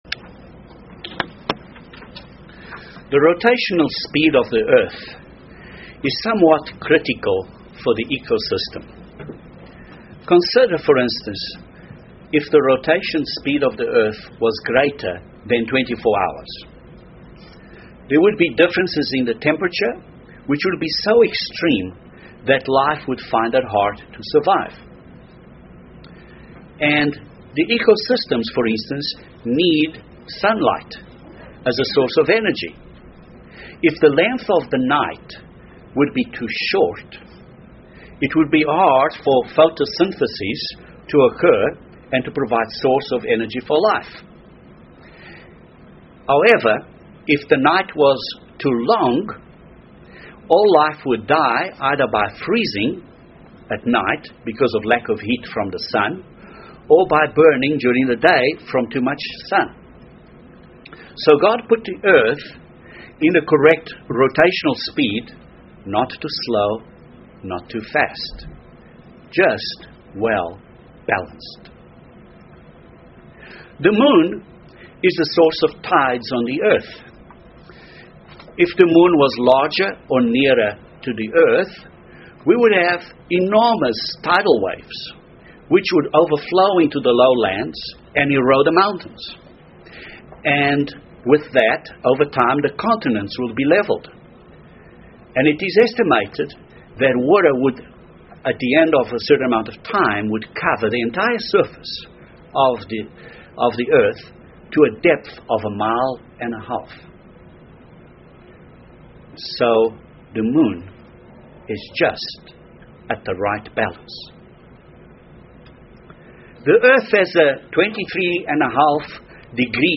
This sermon addresses a few biblical principles which we may apply to be sound (balanced) in mind and heart.